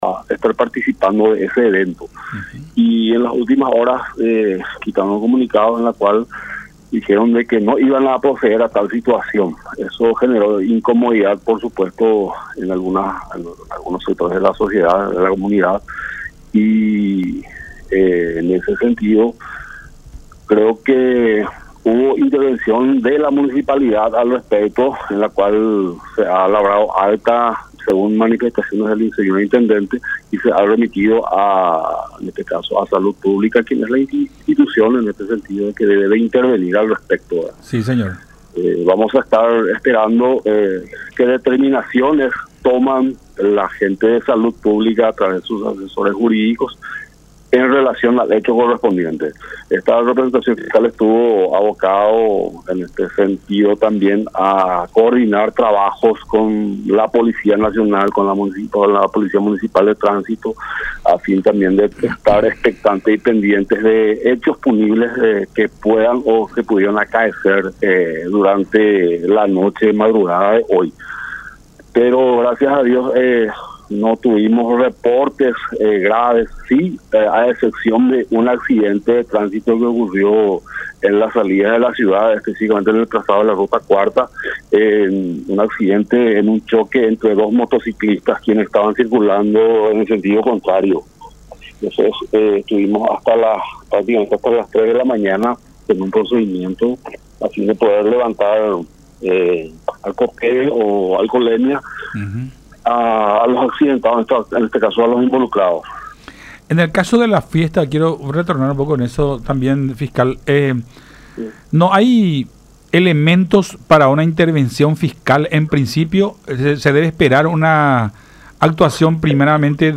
“En principio, los organizadores afirmaron que iban a pedir el carnet de vacunación para que los asistentes puedan participar de esta fiesta. Sin embargo, a través de un comunicado señalaron que finalmente no lo iban a pedir, lo que generó la molestia de un sector de los asistentes”, dijo el fiscal del caso, David Cabral, en diálogo con Enfoque 800 a través de La Unión.